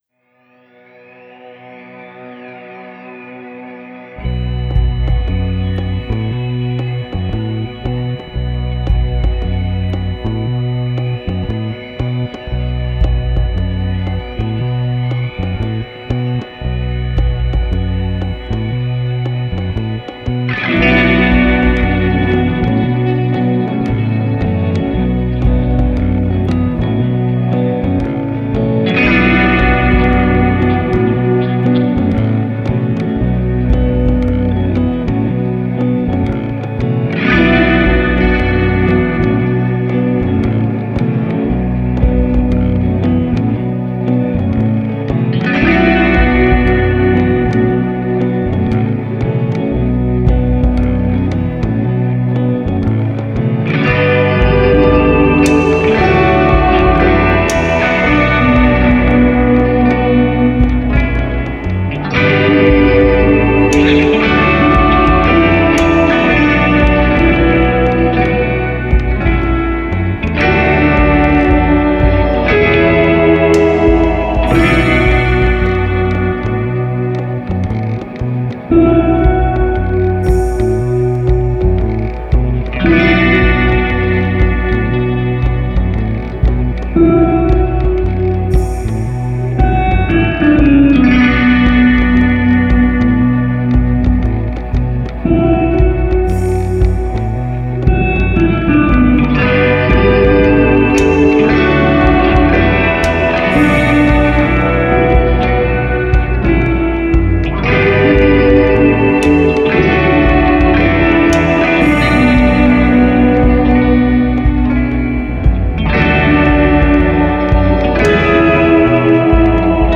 Sustained six string mutations.
Cinematic mysterious ambience with a western touch.